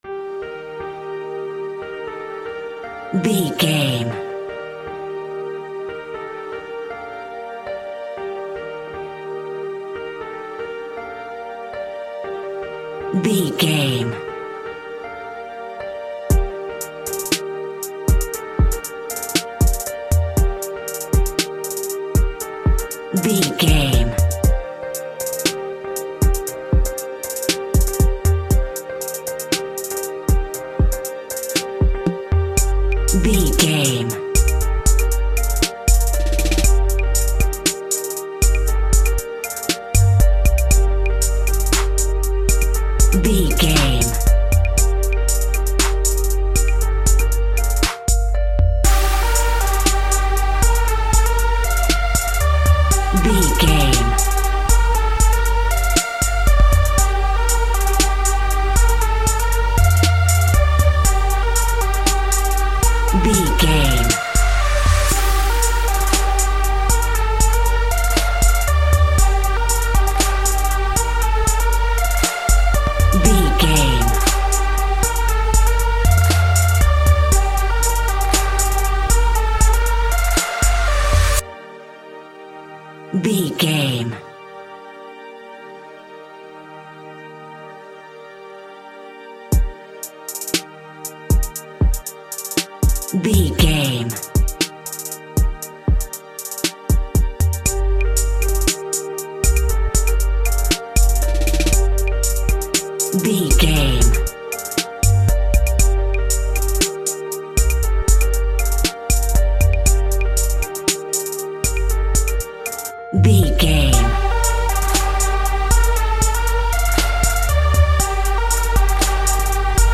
Aeolian/Minor
Slow
instrumentals
laid back
groove
hip hop drums
hip hop synths
piano
hip hop pads